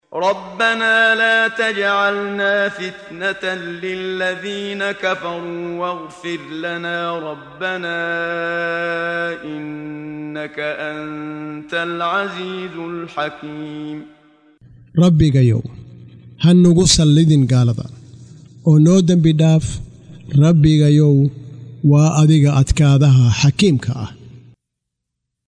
قراءة صوتية باللغة الصومالية لمعاني سورة الممتحنة مقسمة بالآيات، مصحوبة بتلاوة القارئ محمد صديق المنشاوي - رحمه الله -.